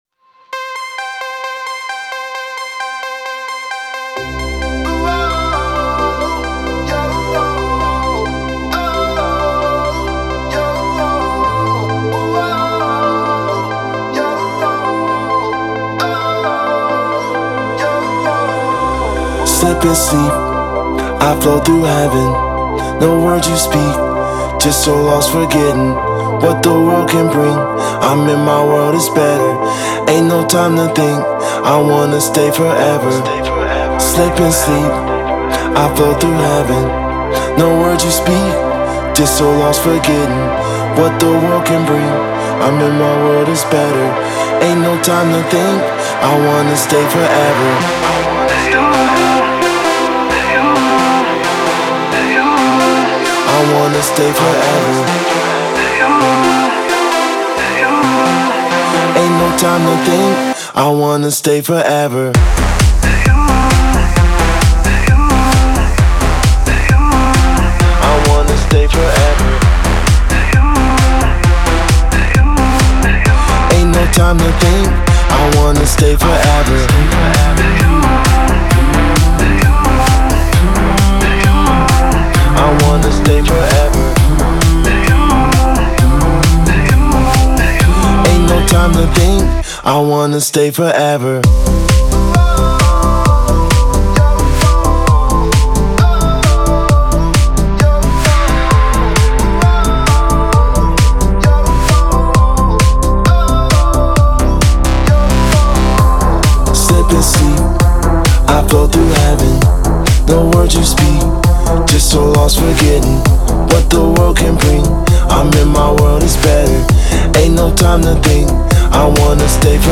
Жанр: Pop, Dance